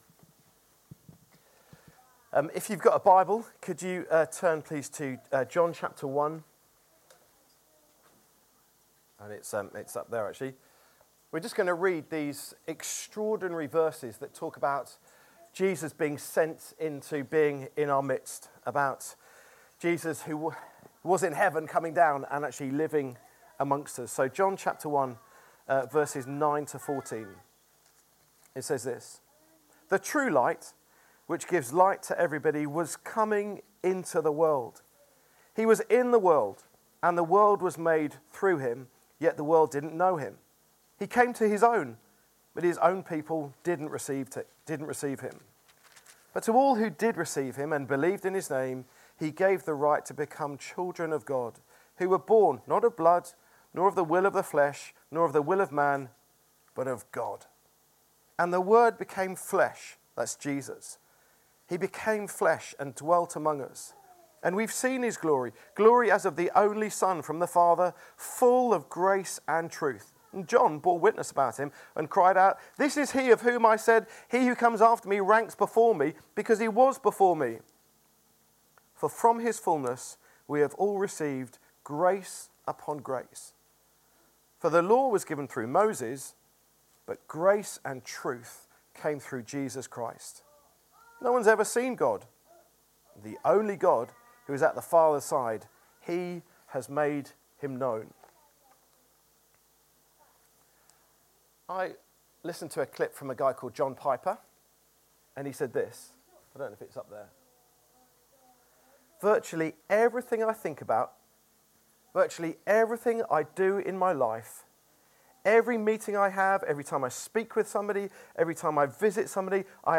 Download Sent To The City | Sermons at Trinity Church